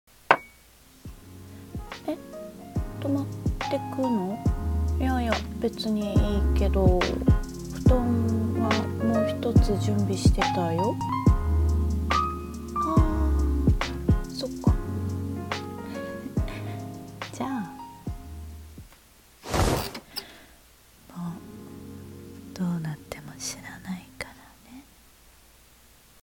もう、どうなっても知らないから 【吐息 シチュエーション】